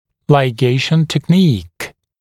[laɪˈgeɪʃn tek’niːk][лайˈгейшн тэк’ни:к]техника лигирования